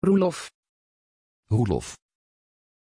Aussprache von Roelof
pronunciation-roelof-nl.mp3